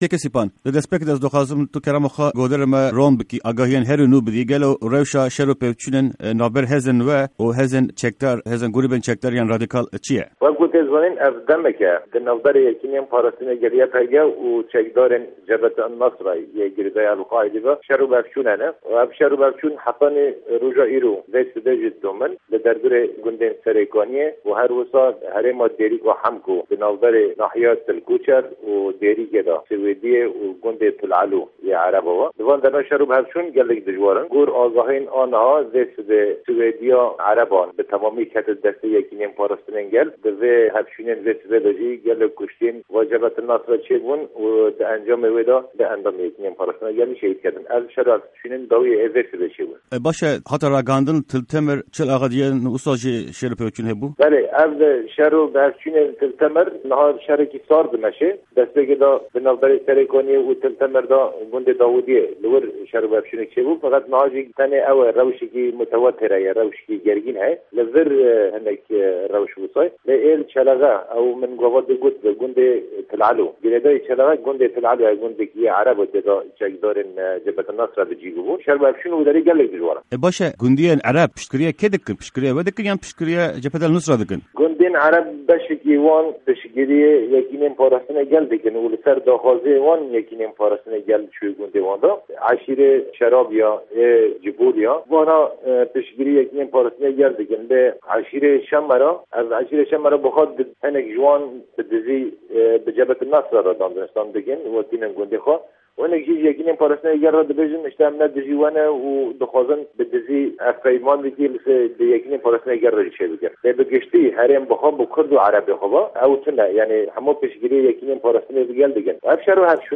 Hevpeyvîn_Sîpan_Hemo_YPG_RR